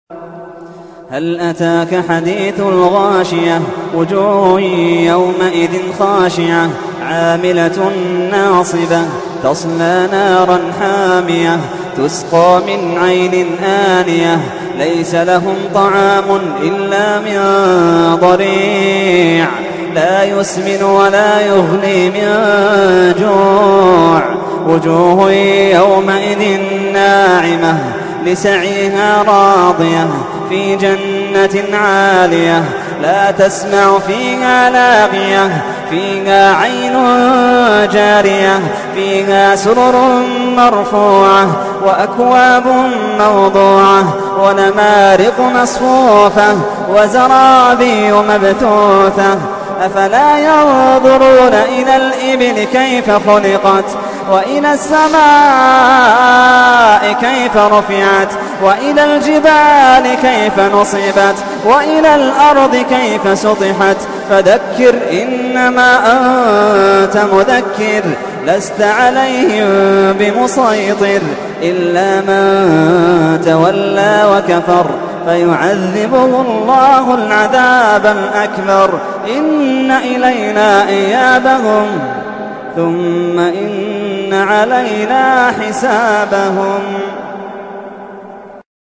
سورة الغاشية / القارئ محمد اللحيدان / القرآن الكريم / موقع يا حسين